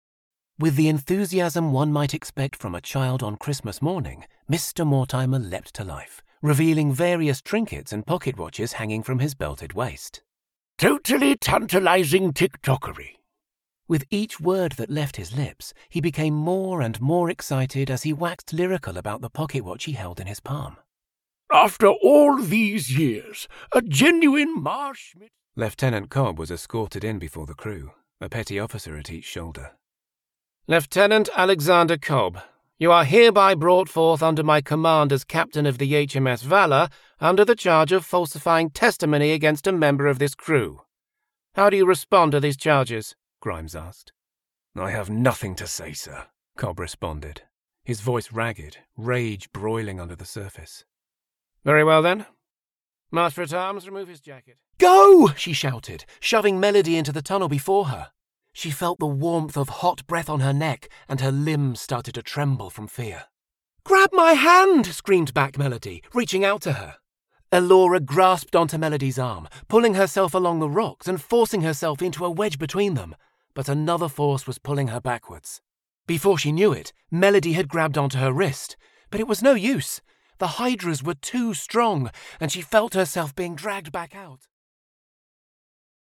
Audiobooks
Session Booth / Neumann TLM103 / Audient iD4 / MacBook Pro / Adobe Audition
BaritoneBassDeepLow
TrustworthyAuthoritativeWarmConversationalFriendly